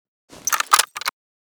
k98_load.ogg